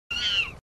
monkey